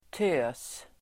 Ladda ner uttalet
tös substantiv, girl Uttal: [tö:s]